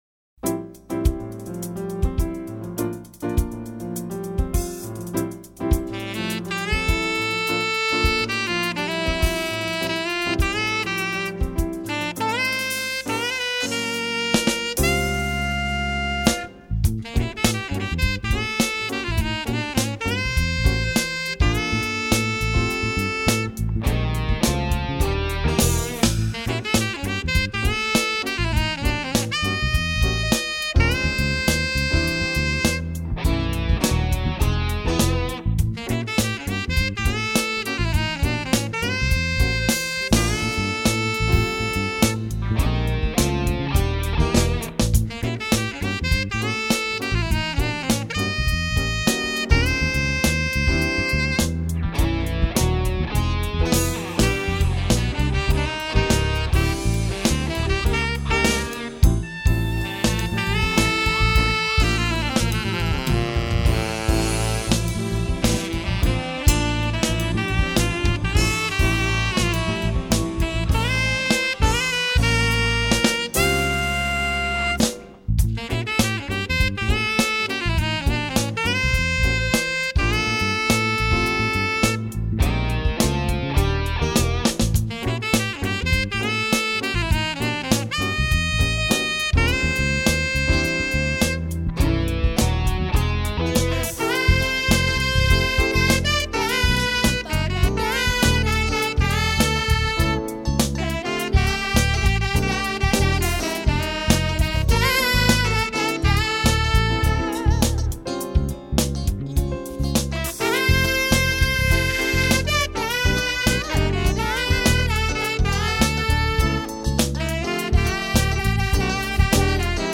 945   08:30:00   Faixa:     Jazz